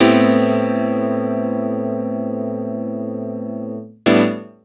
GoodChords-44S.wav